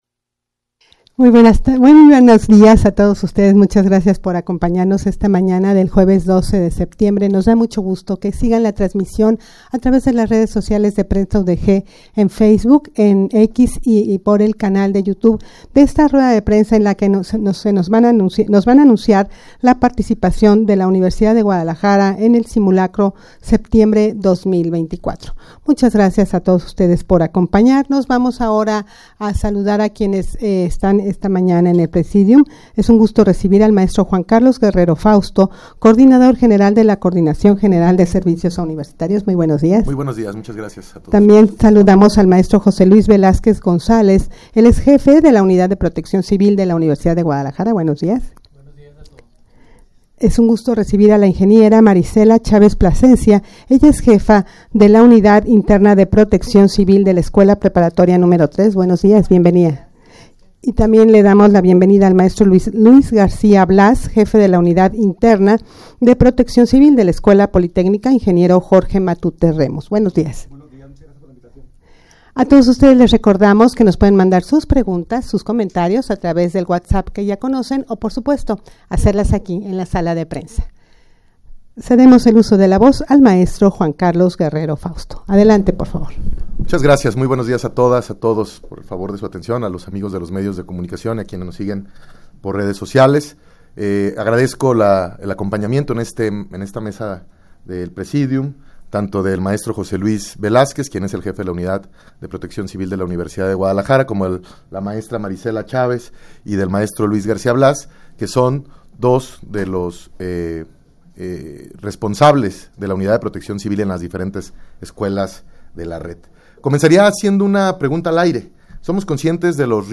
Audio de la Rueda de Prensa
rueda-de-prensa-en-la-que-se-anuncia-la-participacion-de-esta-casa-de-estudios-en-el-simulacro-septiembre-2024.mp3